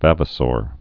(văvə-sôr, -sr)